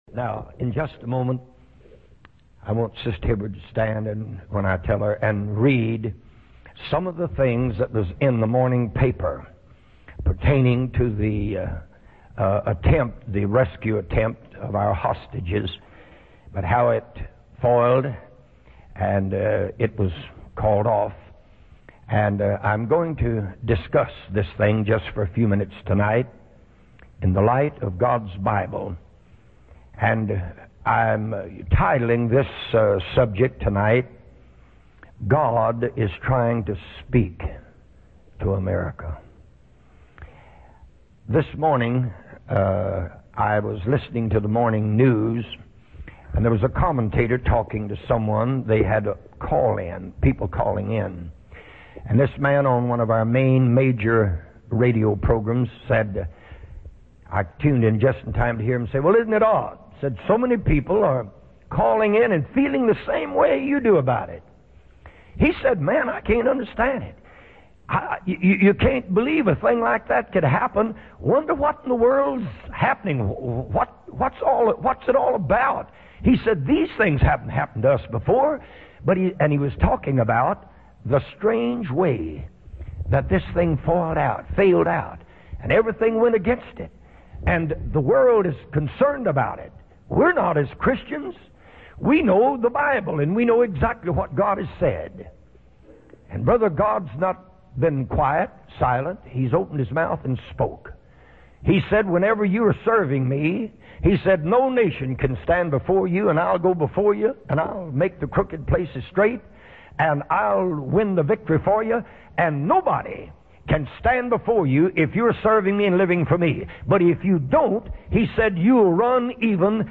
In this sermon, the preacher discusses two individuals who were raised in a Pentecostal environment but have strayed from serving God.